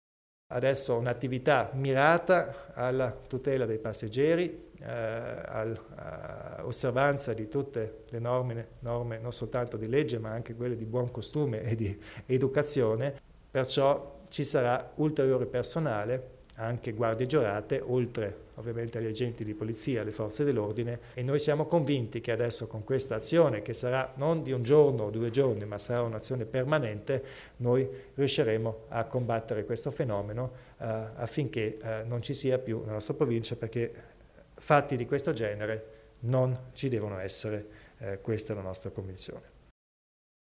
Il Presidente Kompatscher prende posizione sul tema della sicurezza a bordo di treno e bus